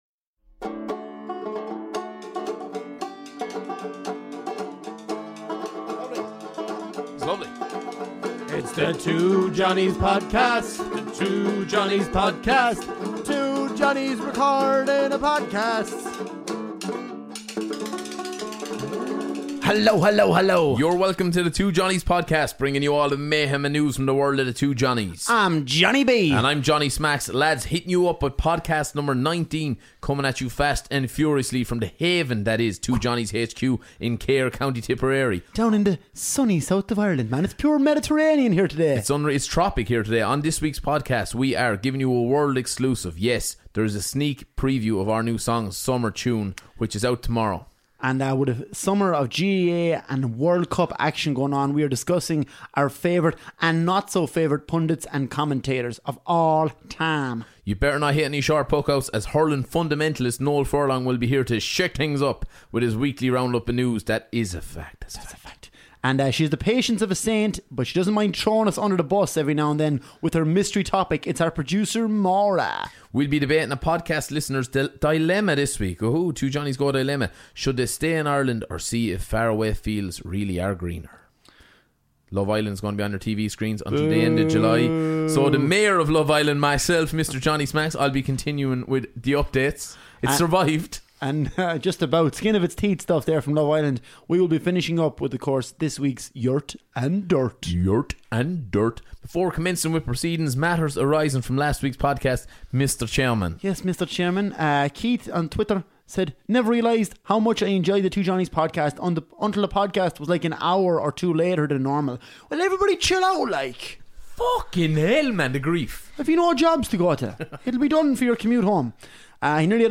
Ireland's favourite comedy duo tackle the big issuesThis week: Stay or go?? Emigrating from Ireland - the big debate. Our favourite and least loved Pundits and commentators .